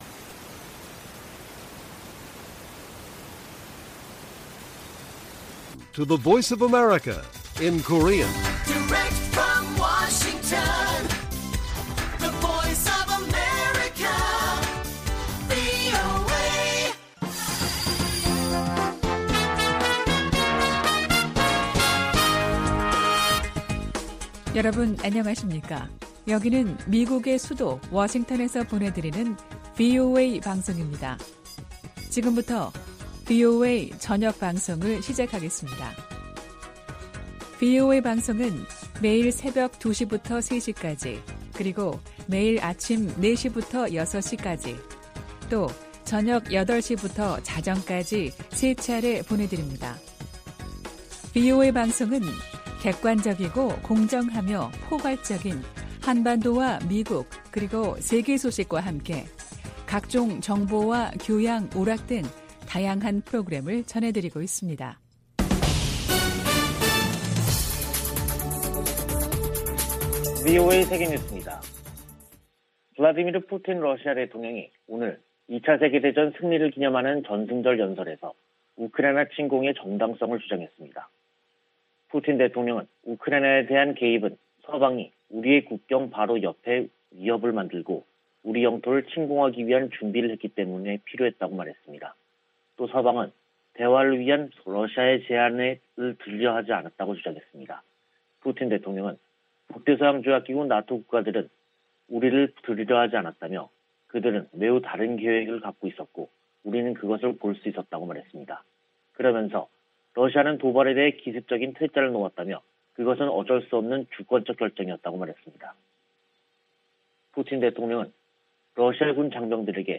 VOA 한국어 간판 뉴스 프로그램 '뉴스 투데이', 2022년 5월 9일 1부 방송입니다. 북한이 7일 오후 함경남도 신포 해상에서 잠수함발사 탄도미사일(SLBM)을 발사했습니다. 미 국무부는 미사일 도발을 이어가는 북한을 규탄하고 한・일 양국에 대한 방어 공약을 재확인했습니다. 미 하원에서 오는 12일 올해 첫 대북 정책 청문회가 개최될 예정입니다.